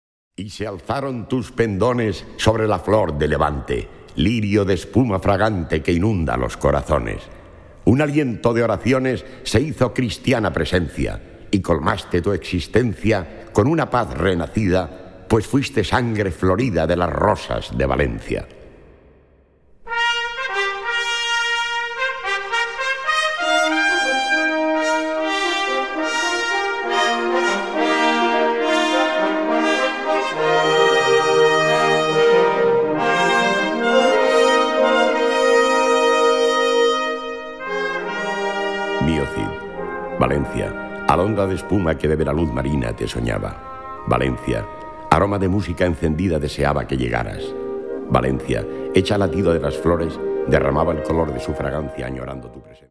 Catégorie Harmonie/Fanfare/Brass-band
Sous-catégorie Récitant et orchestre à vent